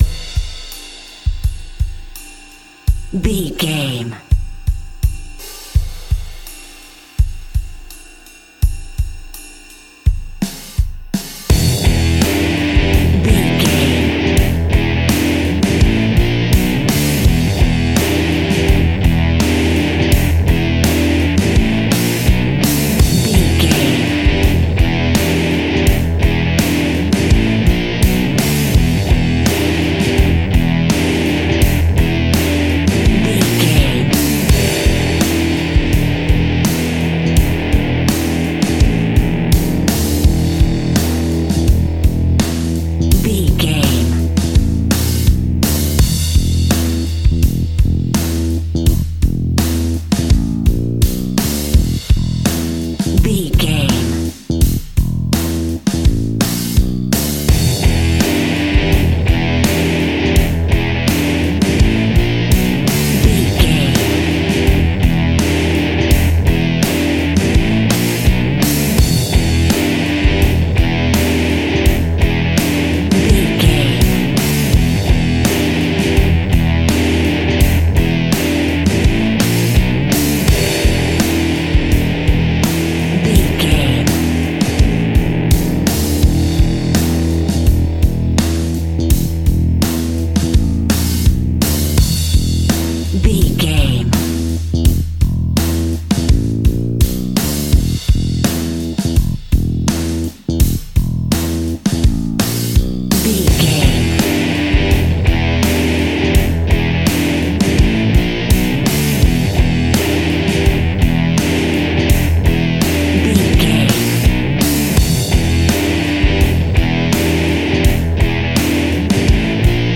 Epic / Action
Fast paced
Aeolian/Minor
hard rock
blues rock
distortion
rock guitars
Rock Bass
heavy drums
distorted guitars
hammond organ